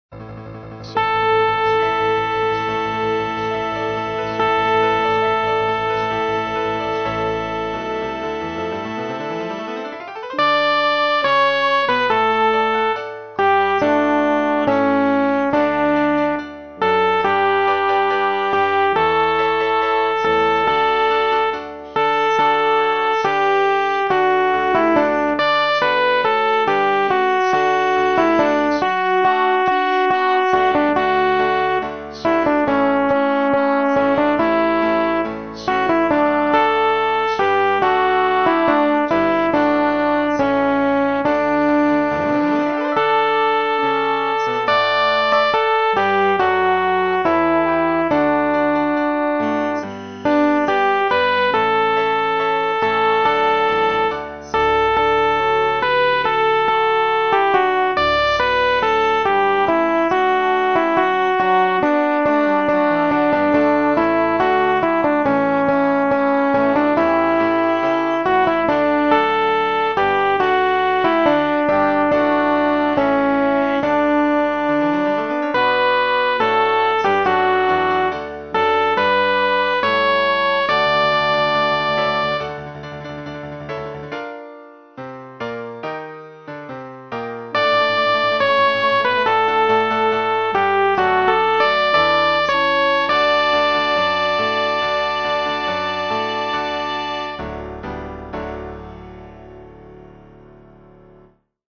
浦和ｗ用　4パート編曲版　テンポ遅い　練習用
アルト（歌詞付き）